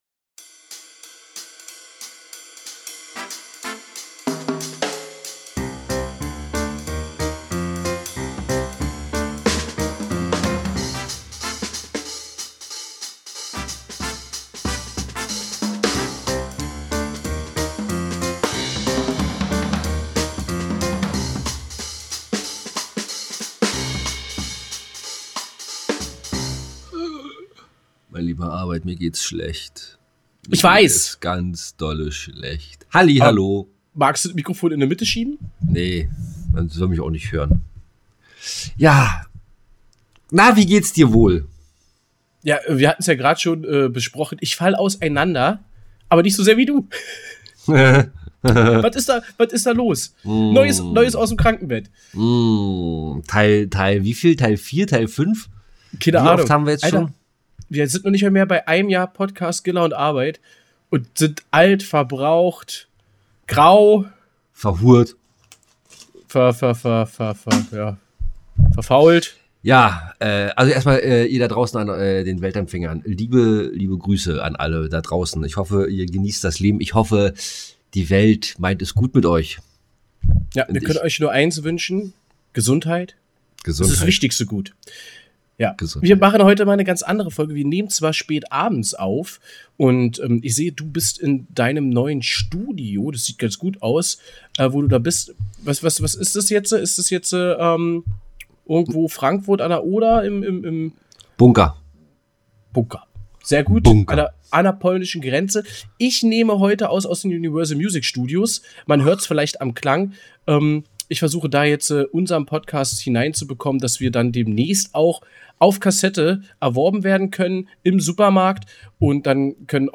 Erleben Sie das Neuste aus dem Kabinett des Bauens gepaart mit Kunstkritik und mal ohne Sport (fast)! Warum das alles wieder aus dem Krankenbett aufgezeichnet wurde erfahrt ihr in der neusten Folge von Gilla & Arbeit.